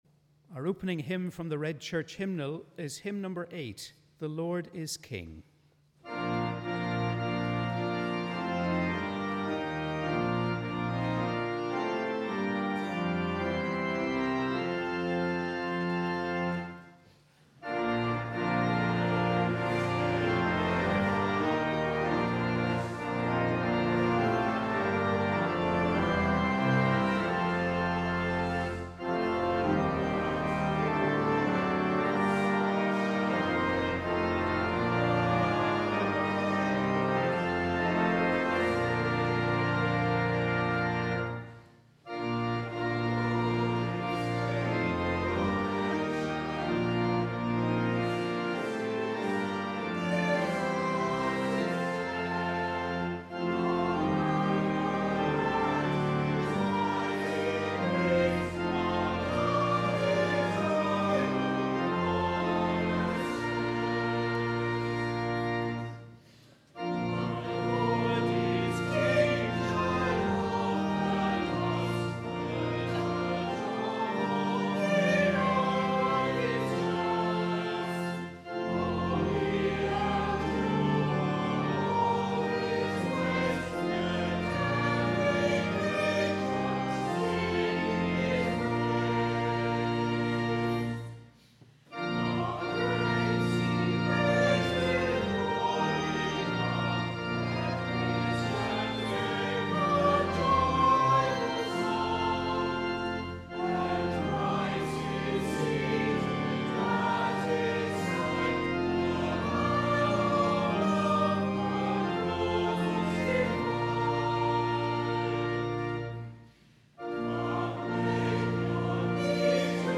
We warmly welcome you to our service of Morning Prayer on the 15th Sunday after Trinity.